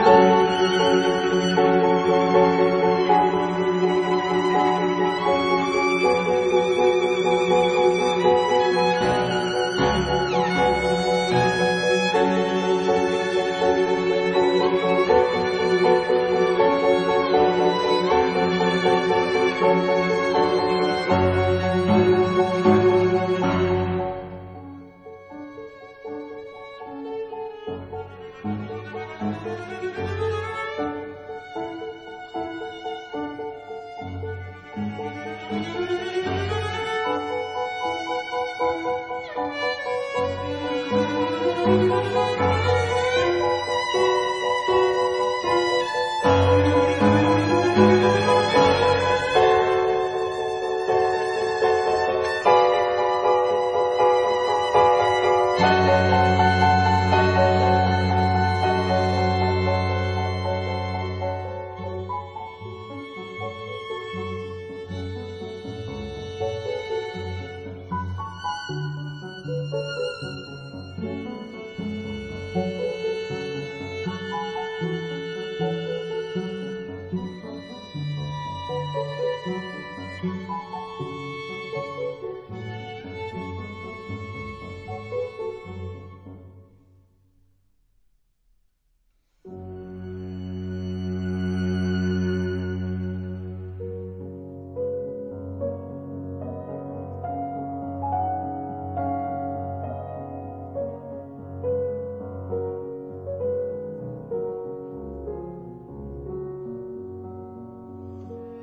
試聽三則是早年的作品，寫給小提琴與鋼琴（編號CFF115），
風格差異很大。